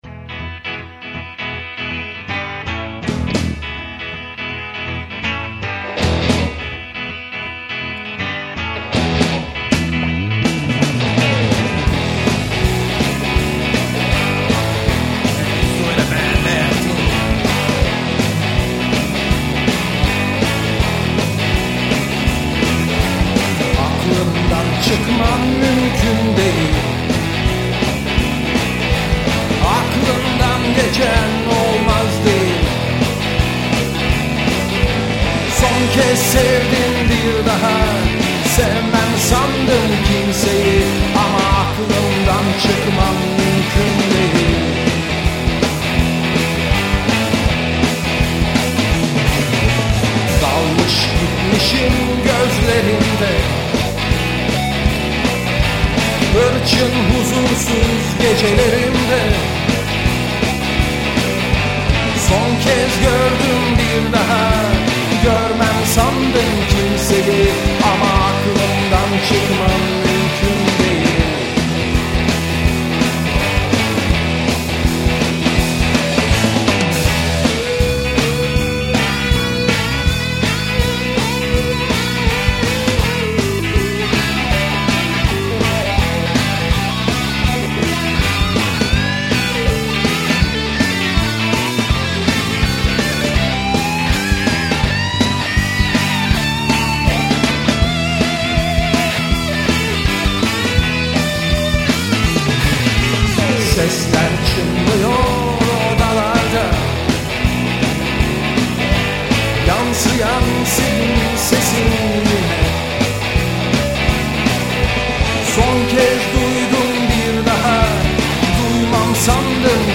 Vokal, elektrik ritim gitar
Bas gitar
Elektrik lead gitar
Davul